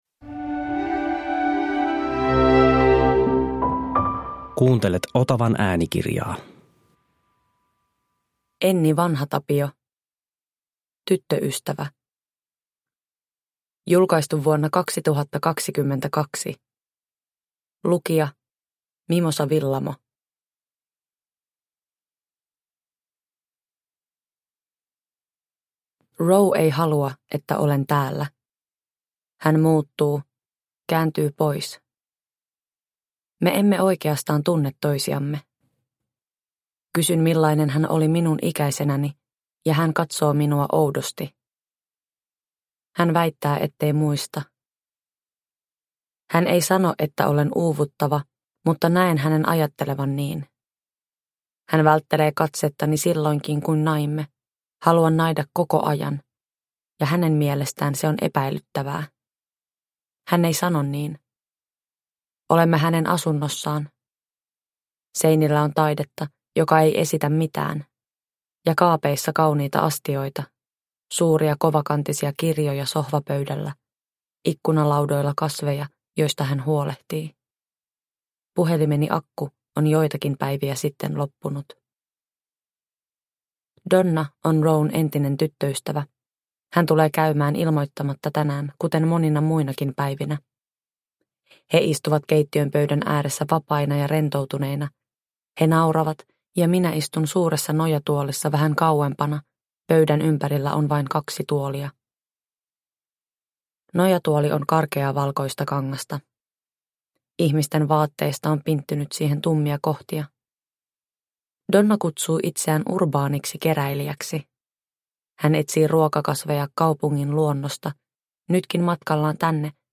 Tyttöystävä – Ljudbok – Laddas ner
Uppläsare: Mimosa Willamo